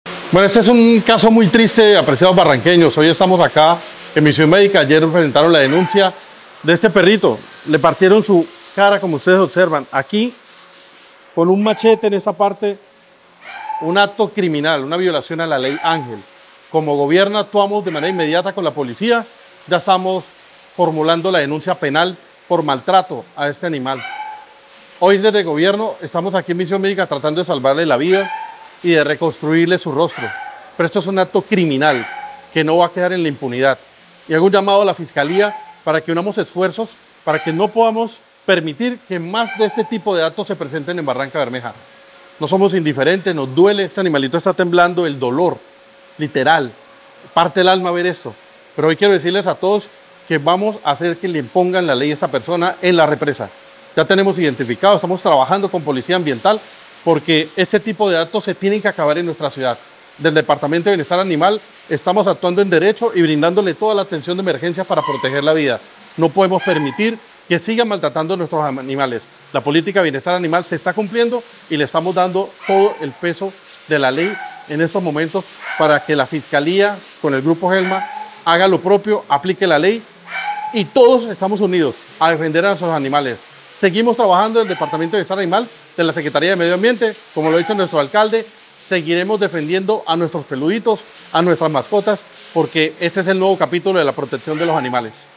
Leonardo Granados, Secretario de Ambiente y Transición Energética